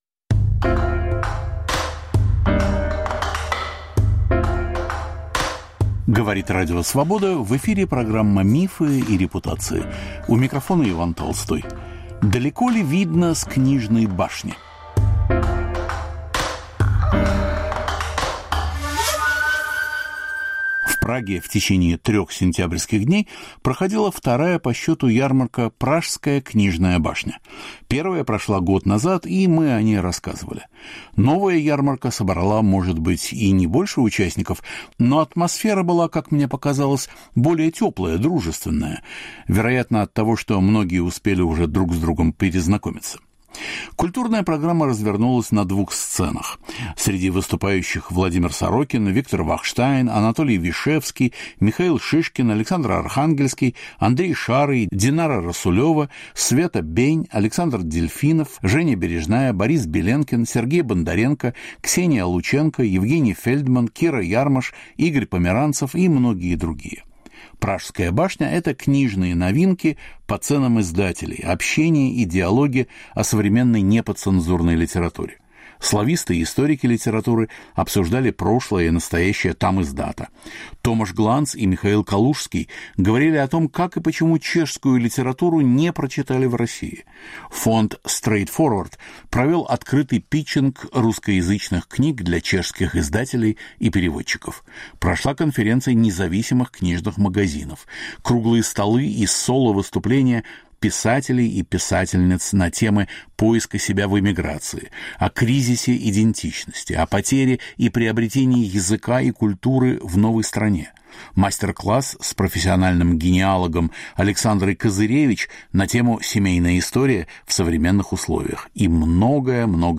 Далеко ли видно с книжной башни? Разговор о книгах, эмиграции, настоящем и будущем.